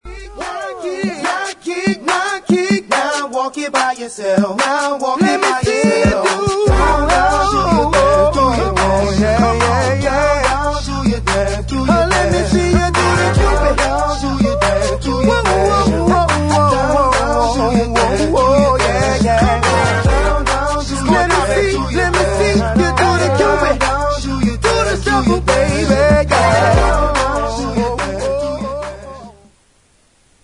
• R&B Ringtones